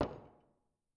add gavel sound
gavel.wav